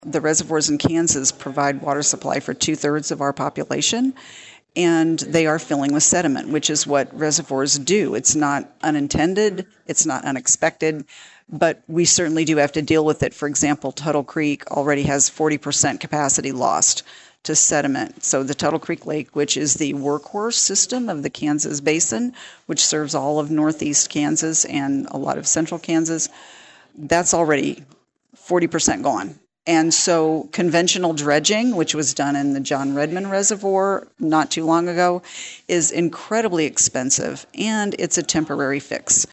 During a break in the meeting, Kansas Water Office Director Connie Owen spoke with KVOE News regarding the state’s water plan.